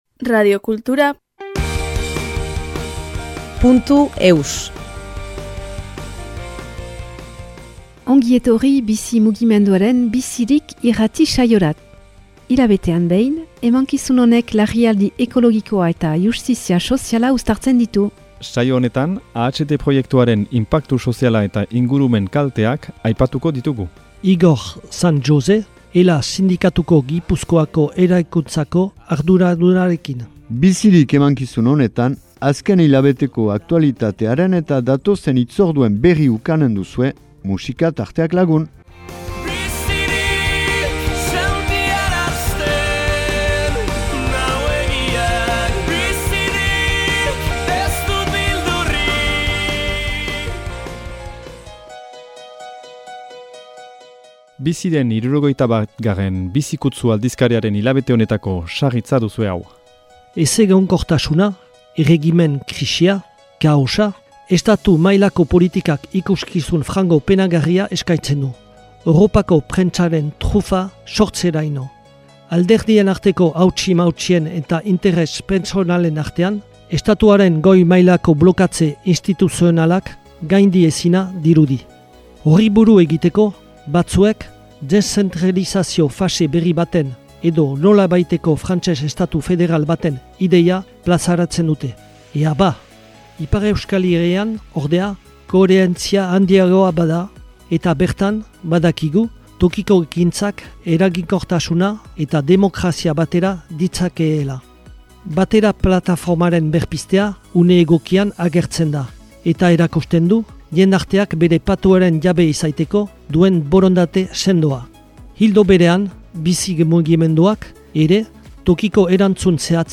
Enregistrement émission de radio en langue basque #129